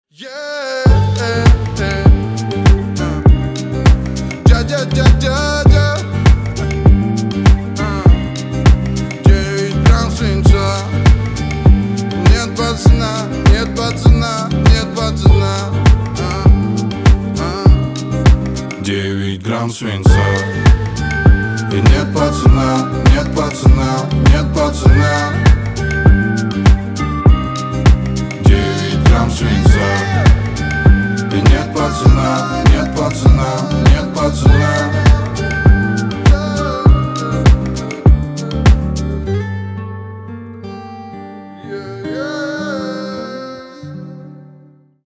• Качество: 320, Stereo
гитара
свист
мужской вокал
Хип-хоп
русский рэп